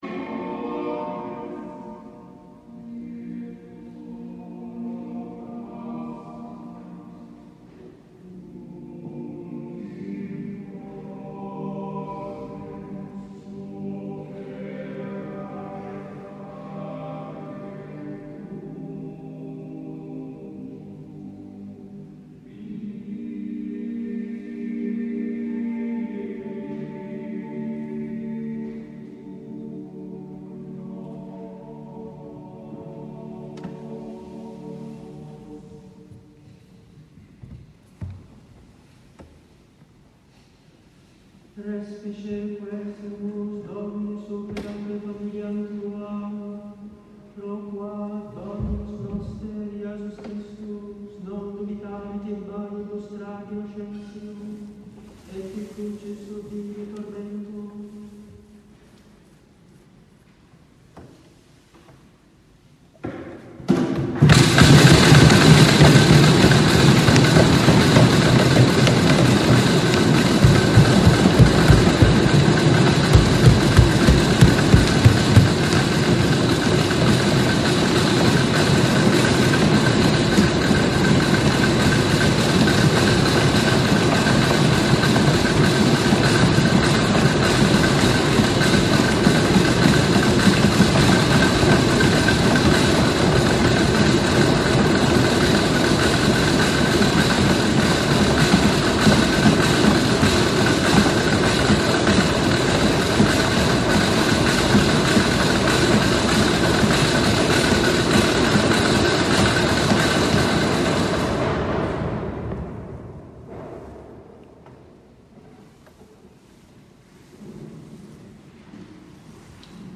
The end of the Miserere and the “earthquake” at the end of Temebrae. Too dark for video.